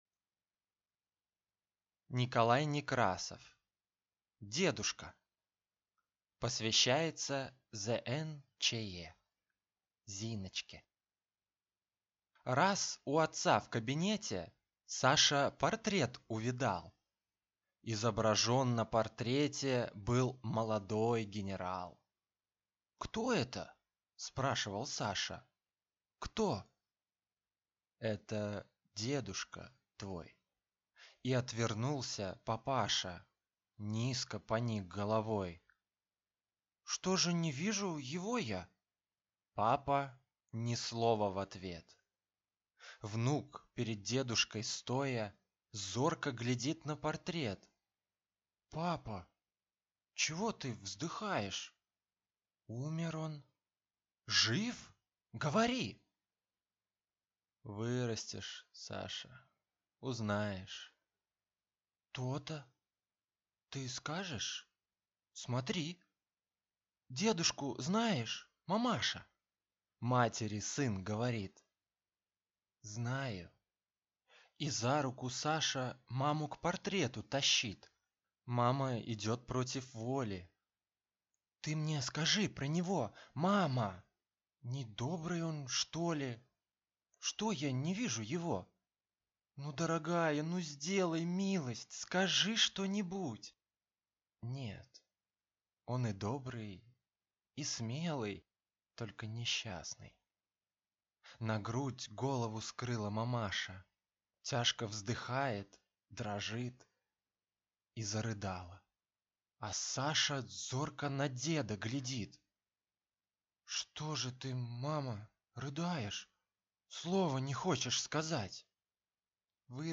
Аудиокнига Дедушка | Библиотека аудиокниг